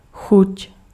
Ääntäminen
Synonyymit palais appétit Ääntäminen France: IPA: [gu] Tuntematon aksentti: IPA: /ɡu/ Haettu sana löytyi näillä lähdekielillä: ranska Käännös Ääninäyte Substantiivit 1. chuť {f} 2. vkus {m} Suku: m .